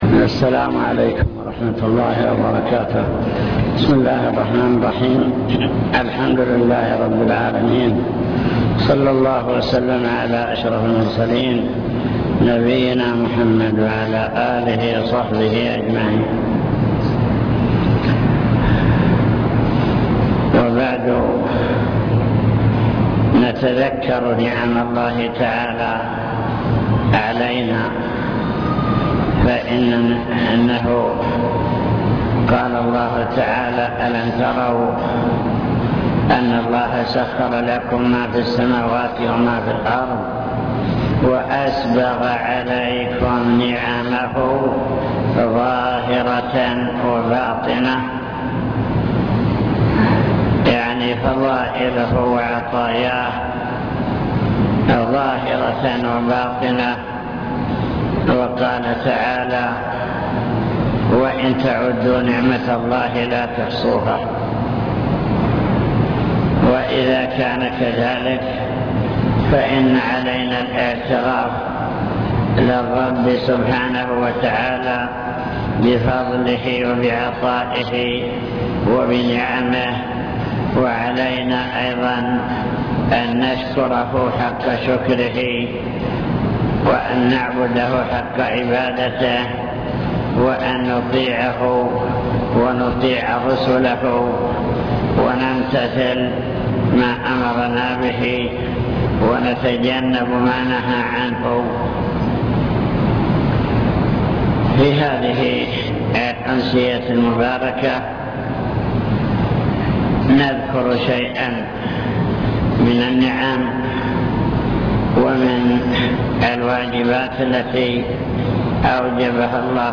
المكتبة الصوتية  تسجيلات - محاضرات ودروس  محاضرة حول توجيهات في العقيدة والأسرة